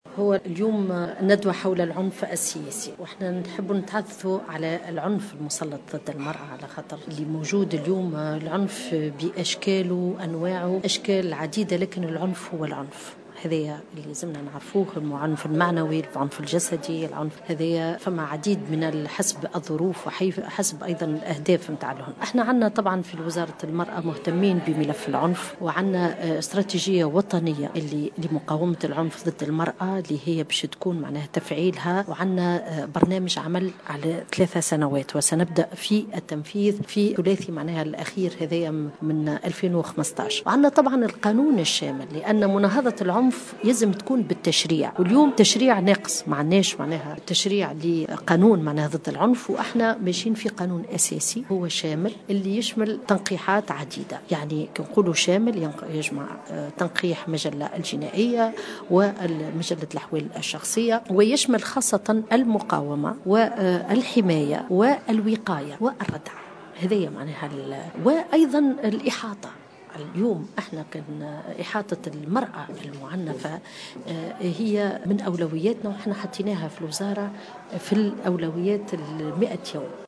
أكدت وزيرة المرأة سميرة مرعي خلال ندوة صحفية عقدت اليوم الخميس 20 أوت 2015 حول "العنف السياسي" أن الوزارة في اتجاه سن قانون أساسي شامل يجرم العنف ضد المرأة.